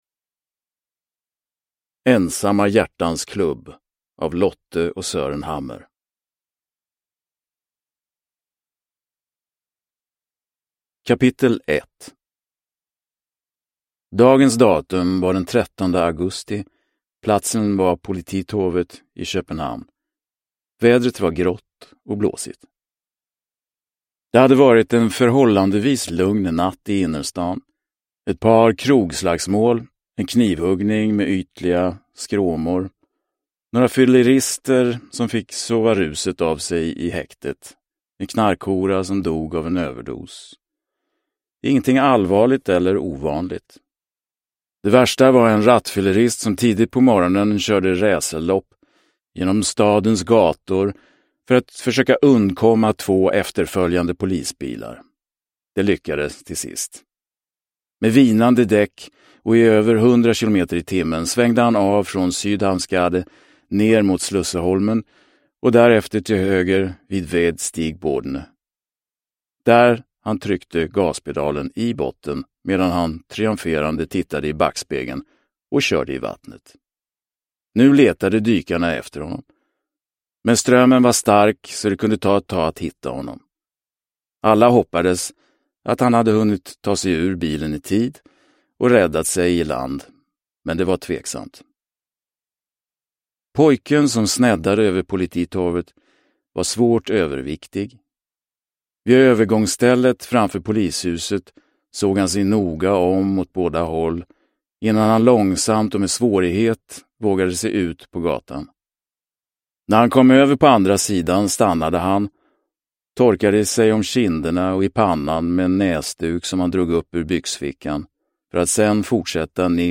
Ensamma hjärtans klubb – Ljudbok – Laddas ner